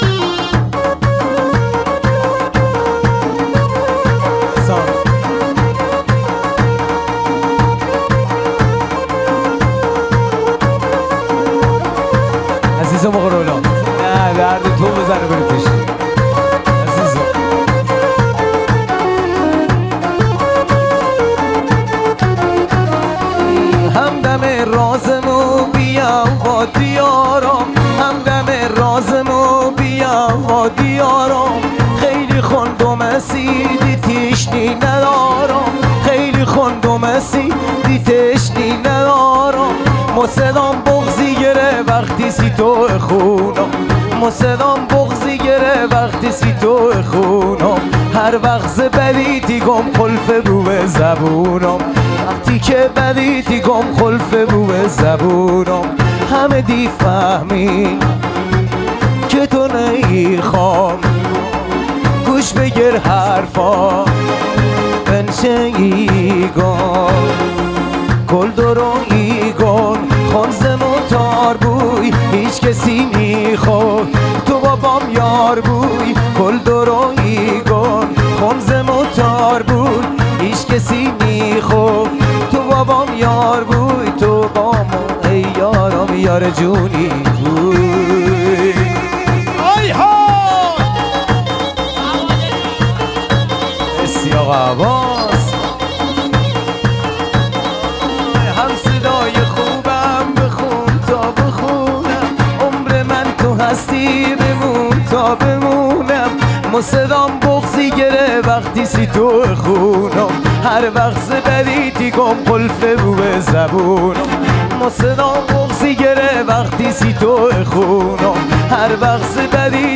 Lori song